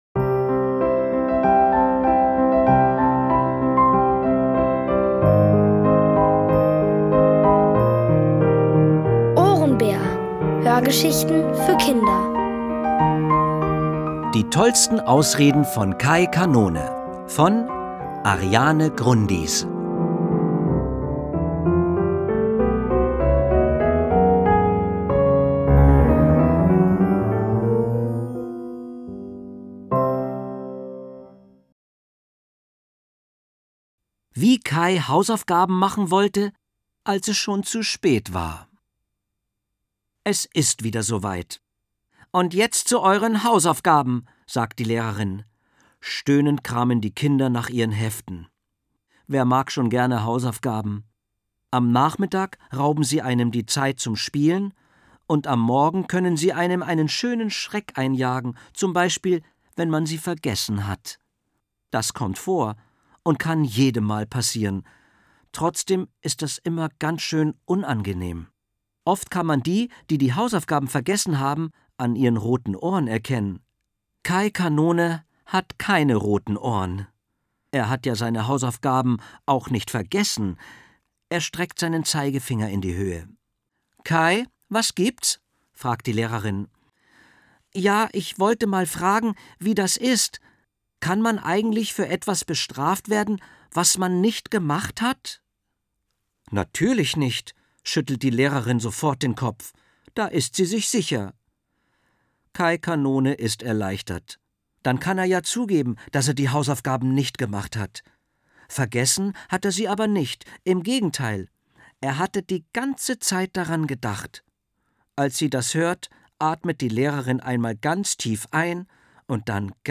Von Autoren extra für die Reihe geschrieben und von bekannten Schauspielern gelesen.
Es liest: Dieter Landuris.